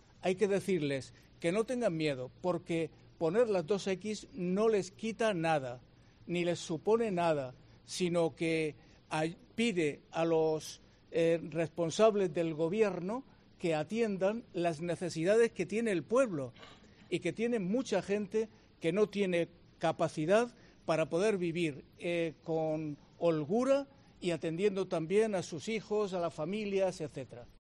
José Manuel Lorca Planes, Obispo de la Diócesis de Cartagena